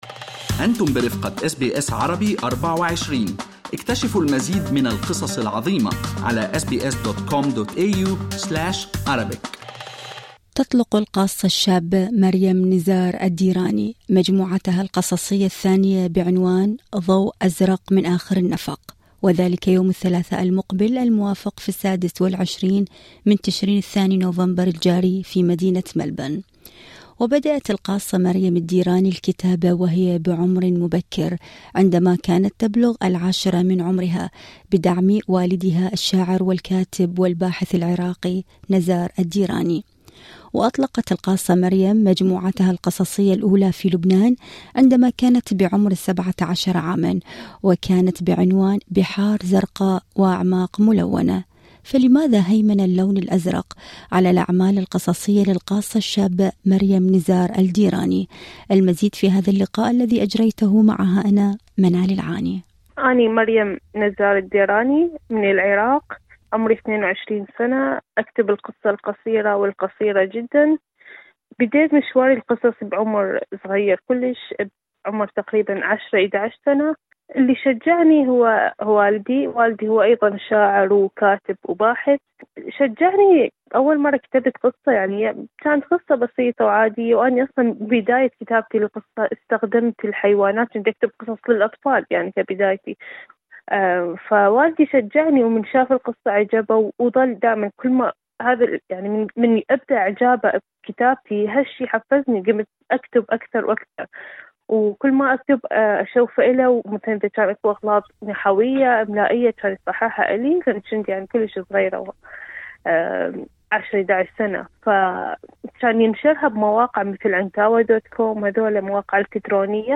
المزيد في المقابلة الصوتية اعلاه هل أعجبكم المقال؟